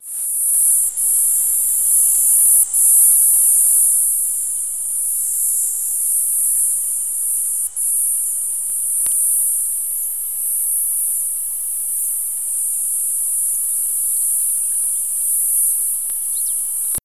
Cigale noire Cicadatra atra